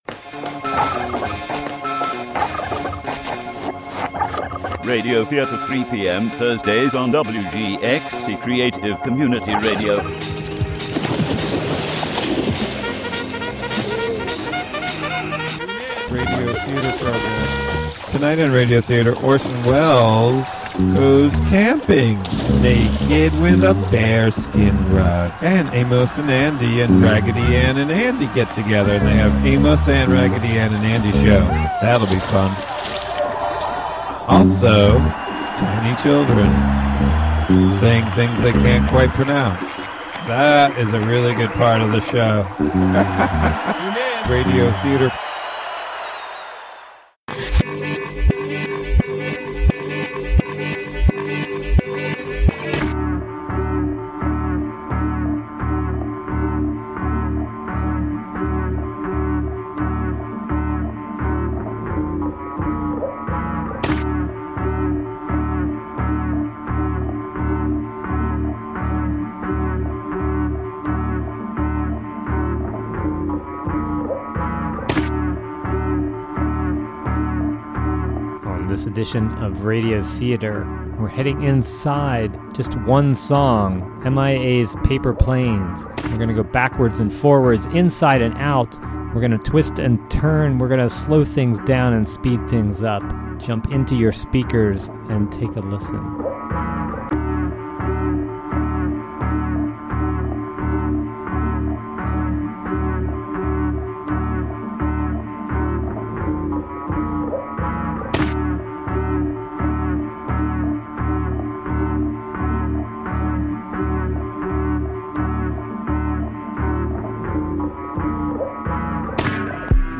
Radio Theatre: Paper Planes (Audio) Dec 03, 2015 shows Radio Theatre Curated by many contributors. broadcasts Paper Planes : Jun 23, 2016: 3pm - 3:30 pm This edition of "Radio Theatre" heads inside of on...